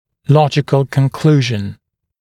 [‘lɔʤɪkl kən’kluːʒn][‘лоджикл кэн’клу:жн]логический вывод, логическое заключение